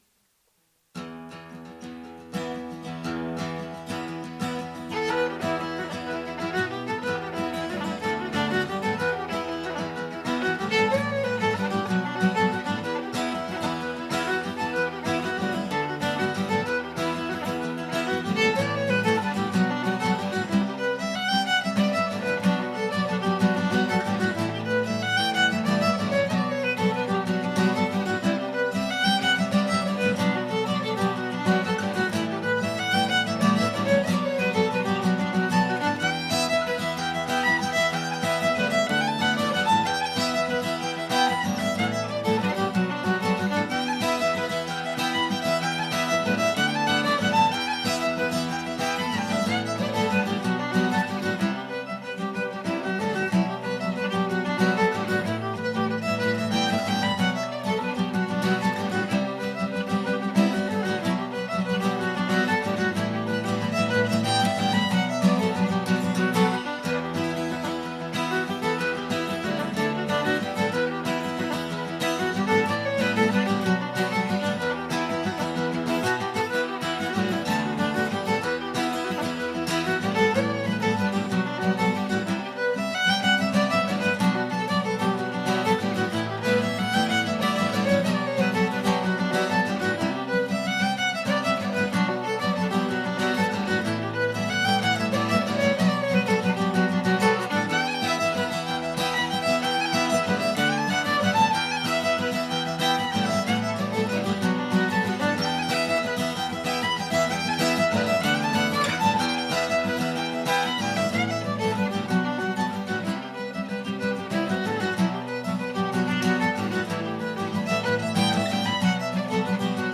Mooncoin Jig set